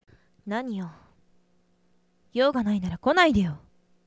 ネット声優に50のお題はこちらお借りしました